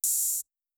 Southside Open Hatz (22).wav